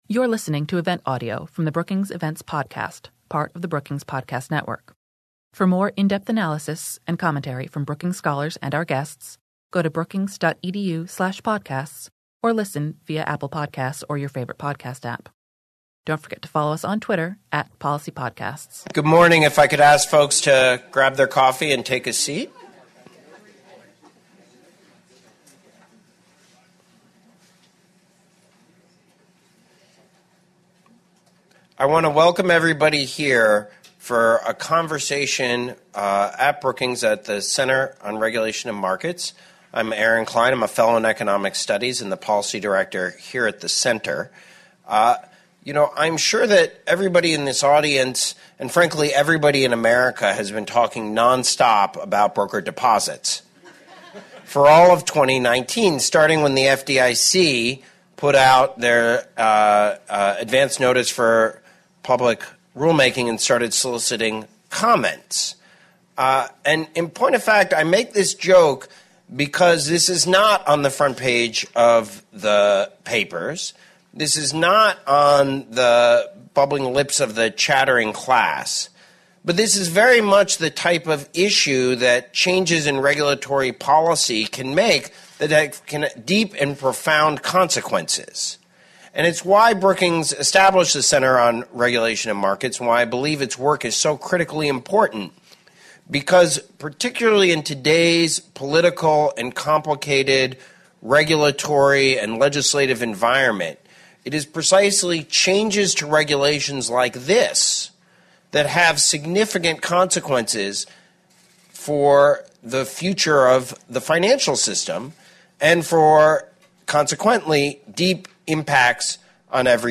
On Wednesday, December 11, the Center on Regulation and Markets at Brookings hosted FDIC Chairman Jelena McWilliams to share her insights and thoughts on the balance between modernizing regulation to adapt to technology while ensuring safety and soundness for depositors.
Fireside chat
Following McWilliams’ speech, a panel of experts offered their perspectives.